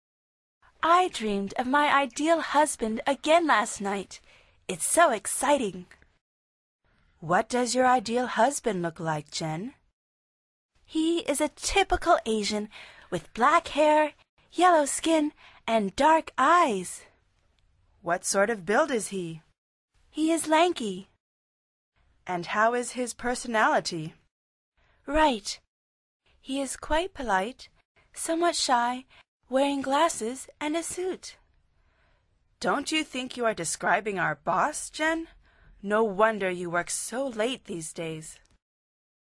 Pulsa las flechas de reproducción para escuchar el primer diálogo de esta lección. Al final repite el diálogo en voz alta tratando de imitar la entonación de los locutores.